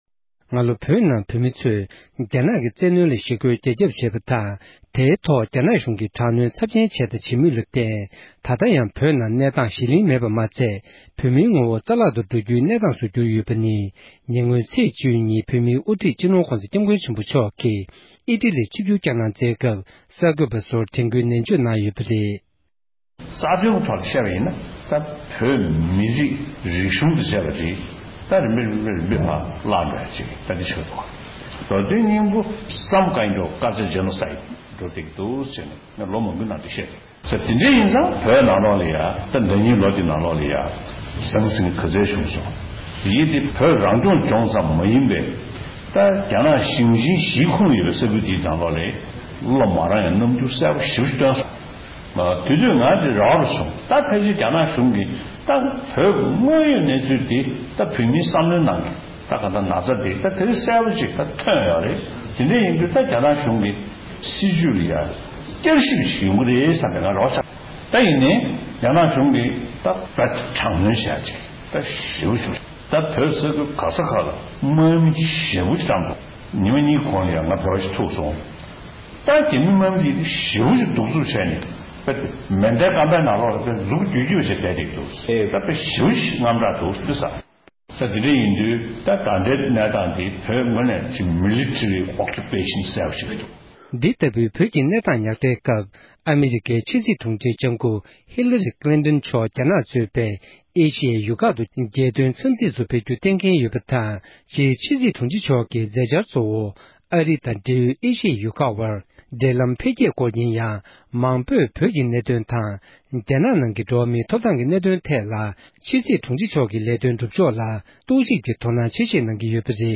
དཔྱད་གཏམ།